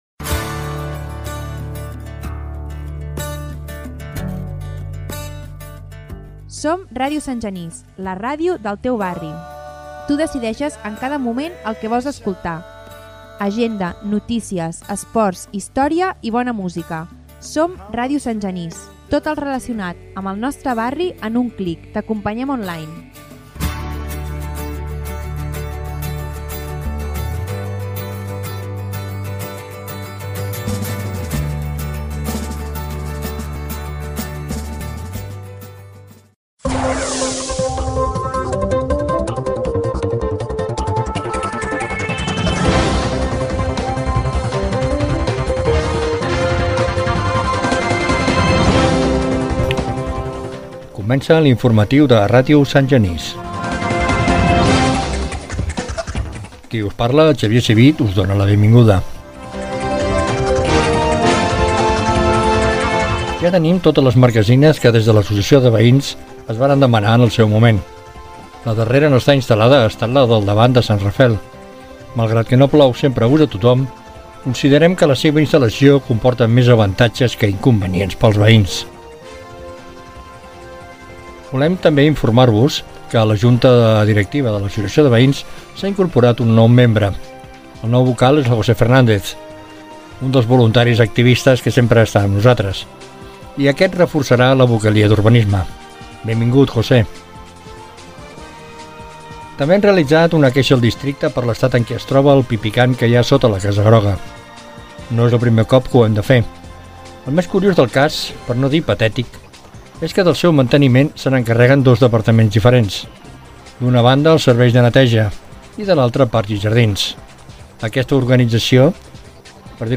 Indicatiu de l'emissora, noves marquesines, nou membre de l'Associació de Veïns, queixa sobre el pipicà, etc.